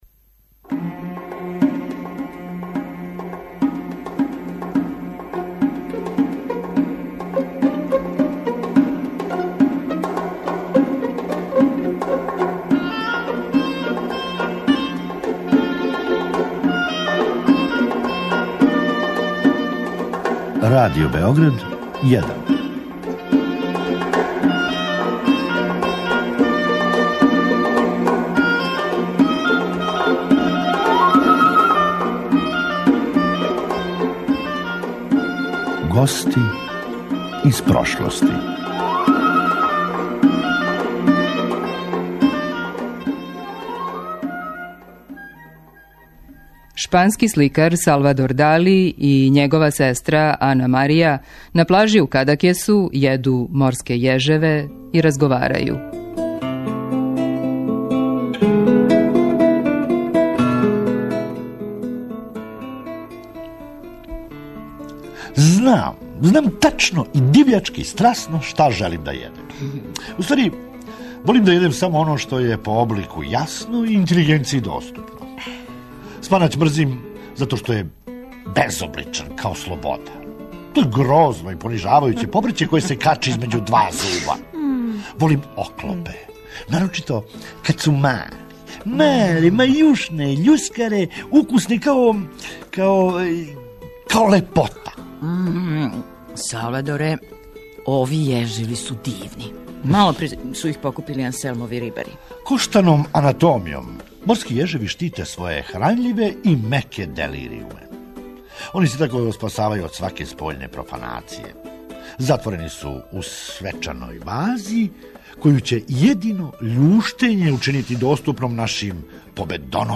'Шпански сликар, Салвадор Дали и његова сестра Ана Марија, на плажи у Кадакесу, једу морске јежеве и разговарају'